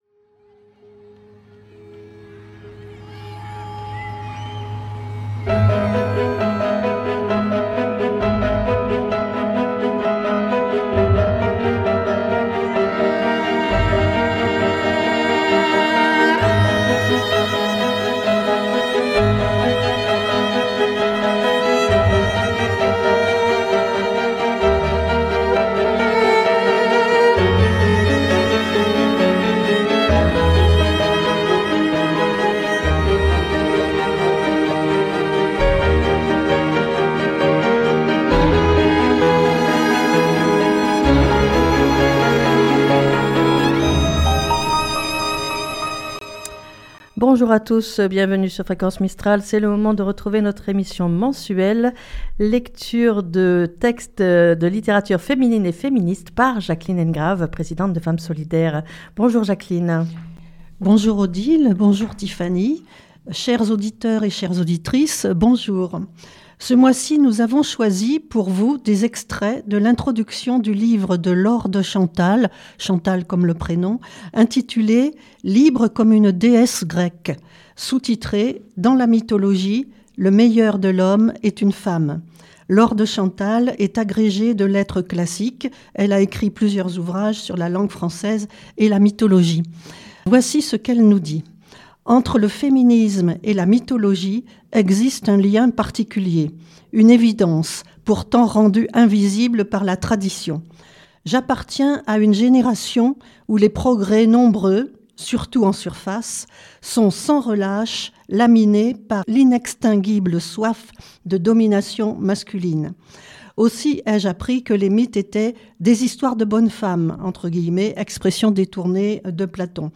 Lecture de textes de littérature féminine et féministe N°8